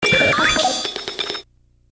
サウンド素材「ポケモン鳴き声」